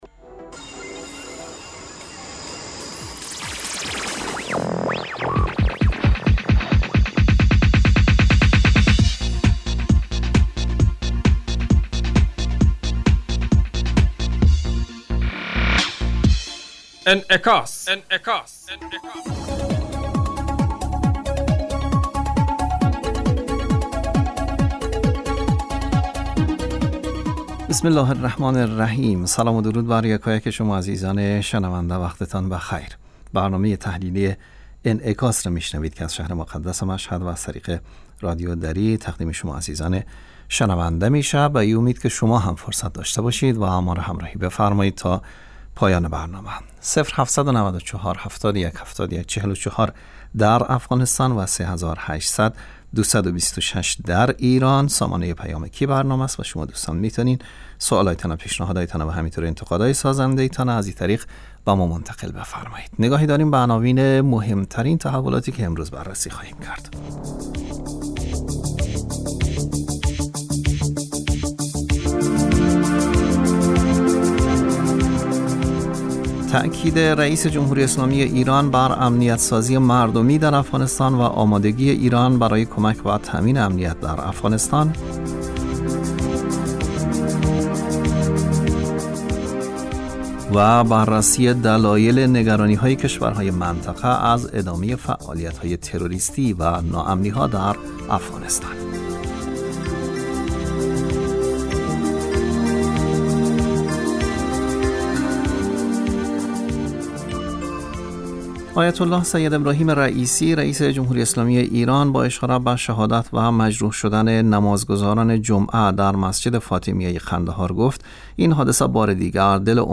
بررسی دلایل نگرانی های کشورهای منطقه از ادامه فعالیت های تروریستی و ناامنی ها در افغانستان. برنامه انعکاس به مدت 30 دقیقه هر روز در ساعت 12:00 ظهر (به وقت افغانستان) بصورت زنده پخش می شود. این برنامه به انعکاس رویدادهای سیاسی، فرهنگی، اقتصادی و اجتماعی مربوط به افغانستان و تحلیل این رویدادها می پردازد.